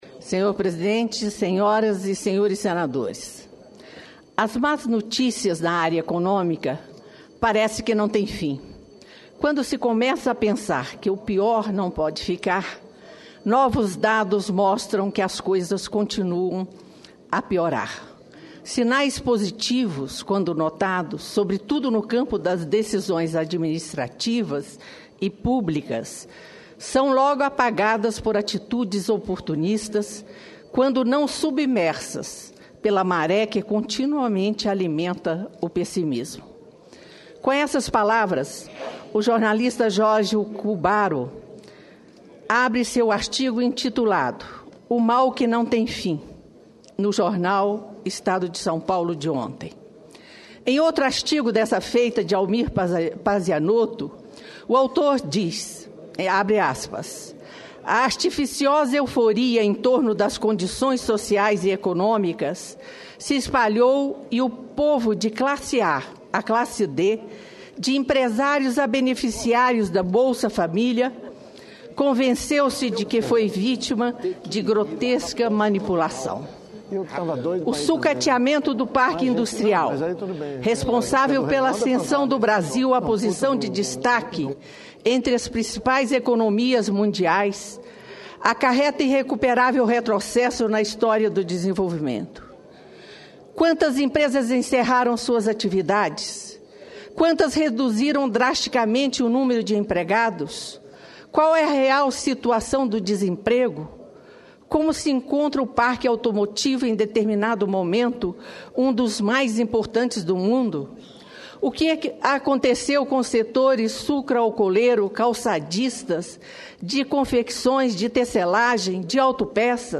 Lúcia Vânia lê artigo sobre as dificuldades da economia no Brasil
Plenário